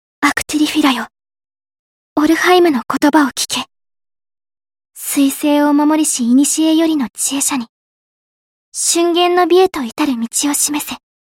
声は可愛いな